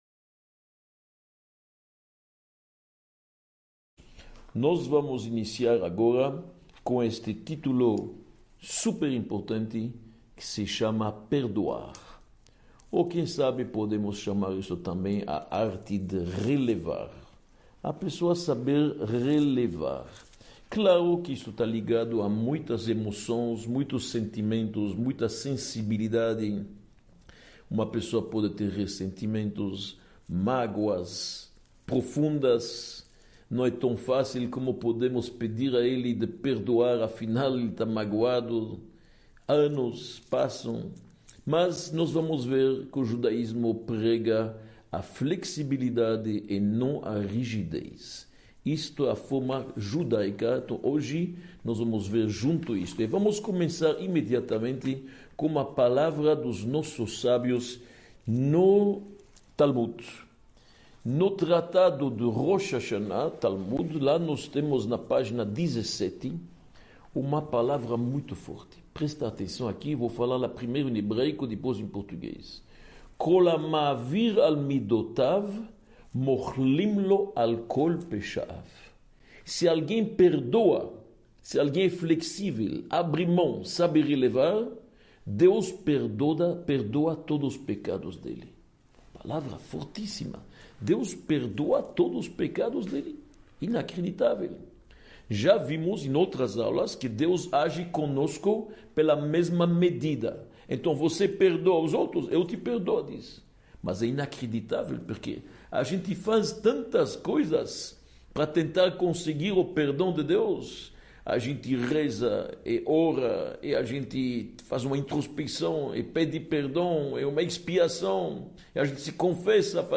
06 – O difícil caminho para perdoar | Comportamento e Atitudes – Aula 06 | Manual Judaico